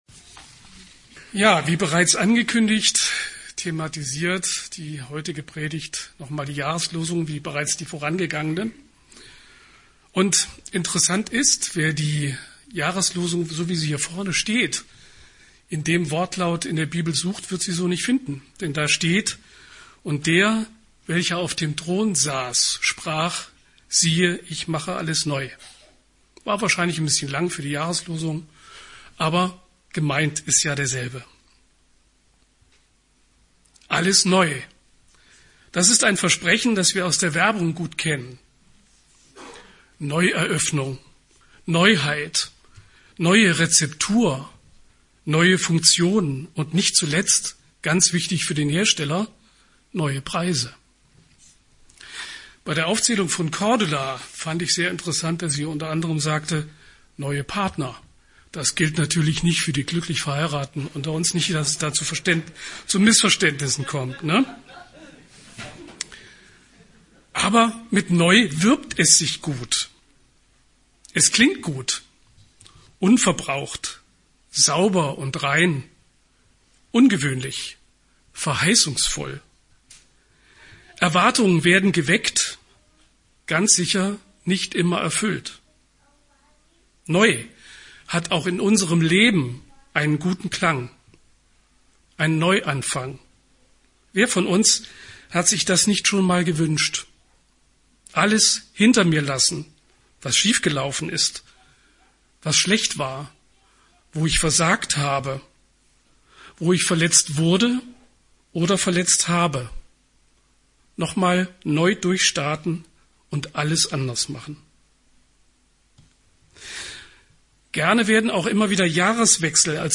Predigten – Christusgemeinde am Airport e.V.